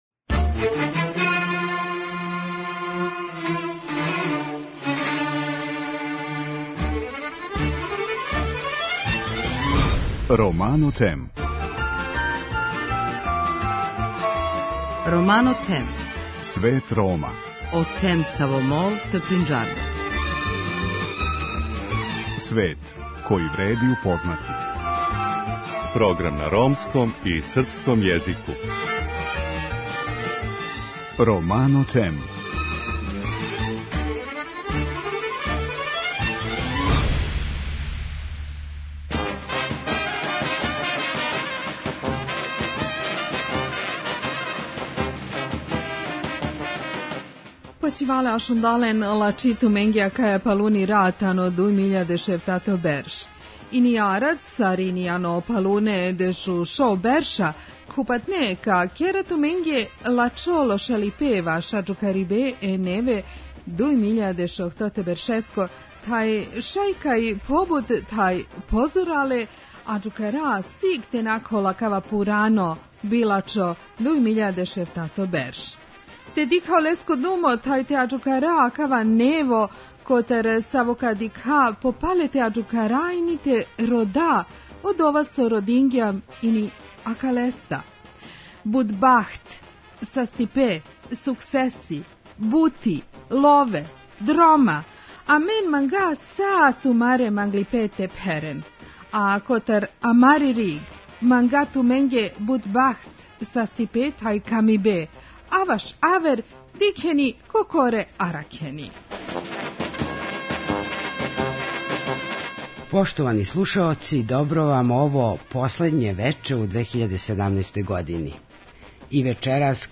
Због тога и ми смо један део емисије посветили нашим редовним слушаоцима и гостима Света Рома који говоре каква им је била стара и шта очекују од Нове Године.